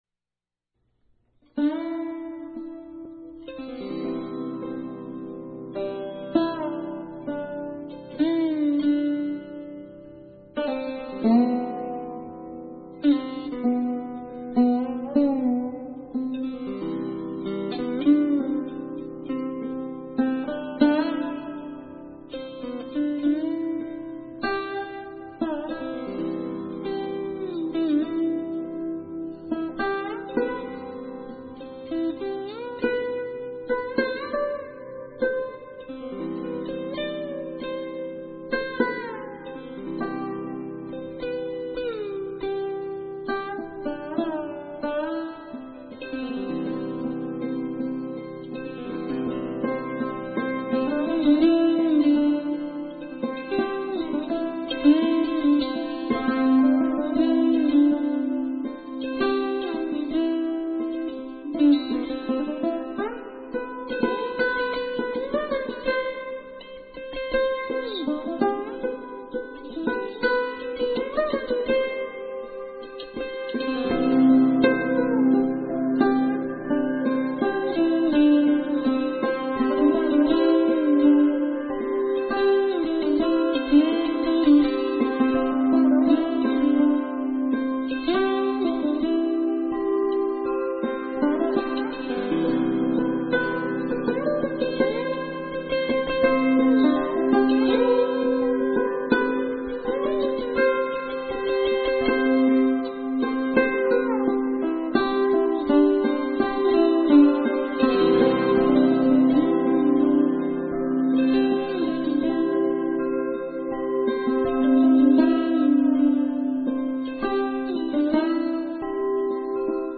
Description Sarod & Indian Gtr. Duo